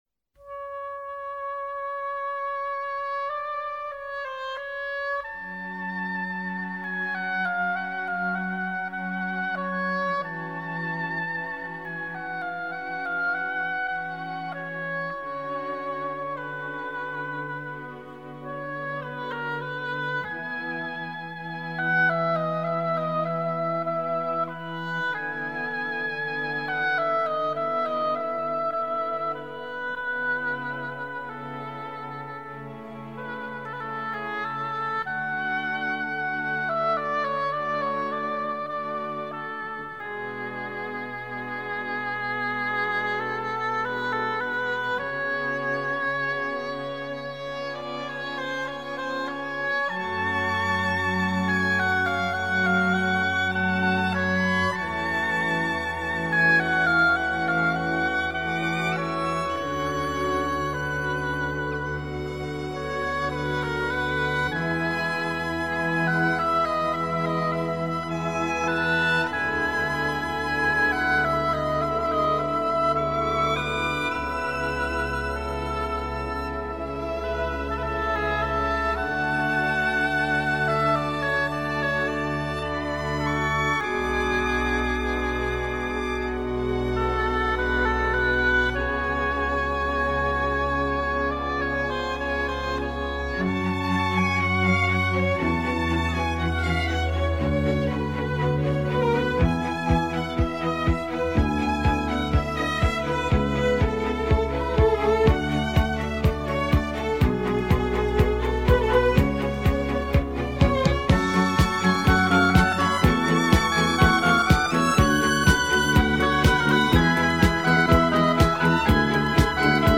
un brano che prima ti rilasserà, poi ti darà energia.
Questo brano strumentale è davvero un relax.